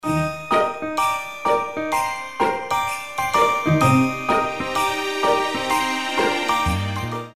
• Качество: 320, Stereo
красивые
мелодичные
без слов
рождественские
Добрая мелодия с рождественским мотивом